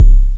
INSKICK19 -L.wav